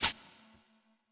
VIRTUOS_Assets/Audio/Soundeffects/SliceGame/Slice-002.wav at main